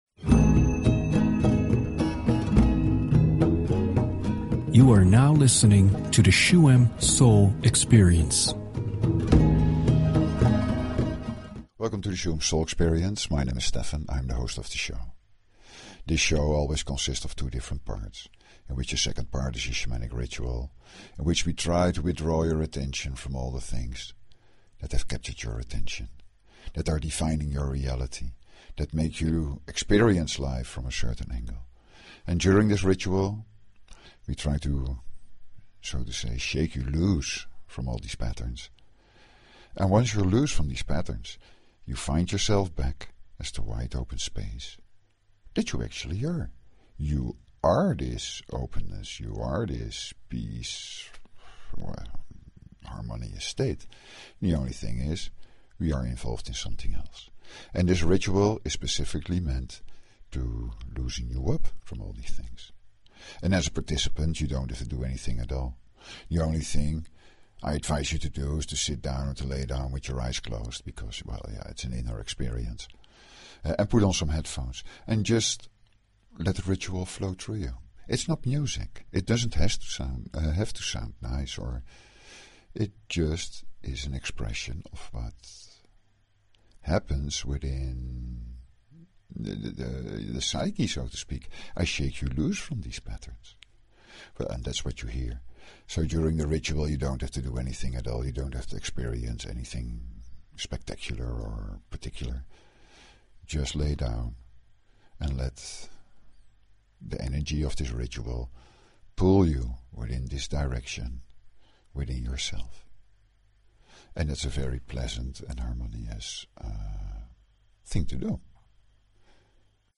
Talk Show Episode, Audio Podcast, Shuem_Soul_Experience and Courtesy of BBS Radio on , show guests , about , categorized as
In the second part of the show you can enjoy a Reset Meditation.
The best way to participate in this meditation is to listen to it through headphones and not to get disturbed.